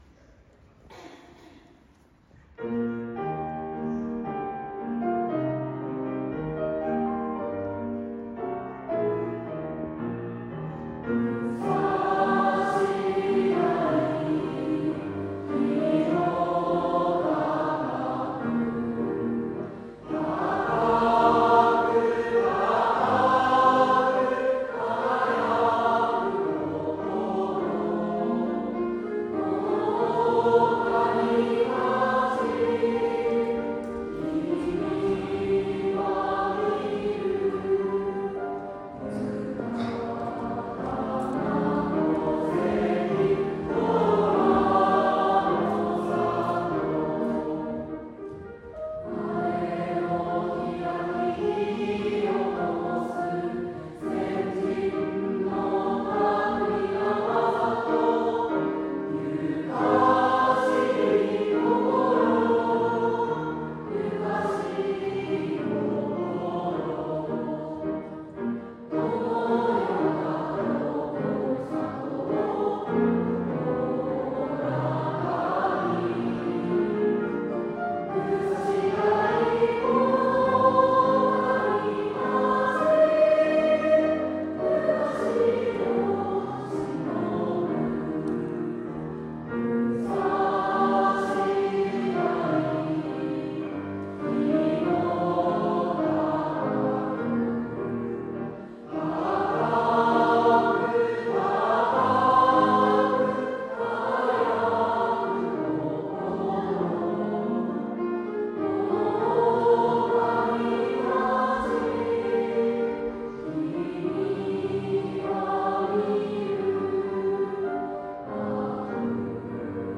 • ・４月２３日（火）音楽朝会
• 、力強い歌声でした。